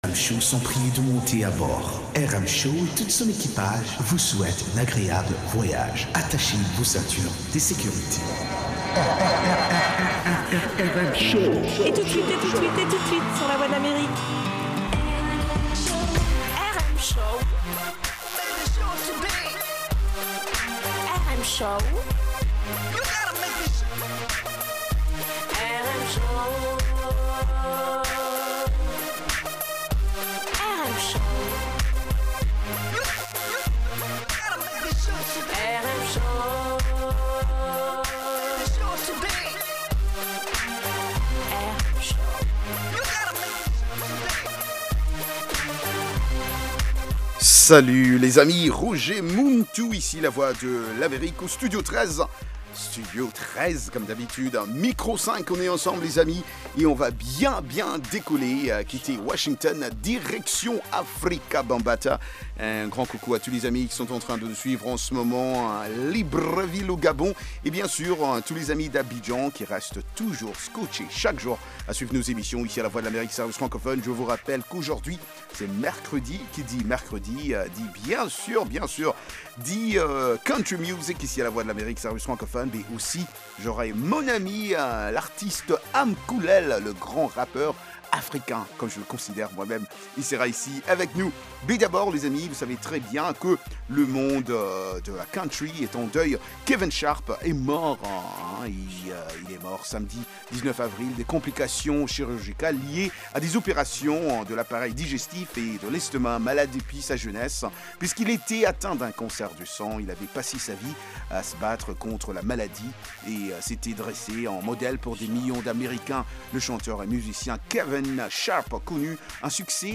Balade musicale dans le monde entier, rions un peu avec de la comédie, interviews des divers artistes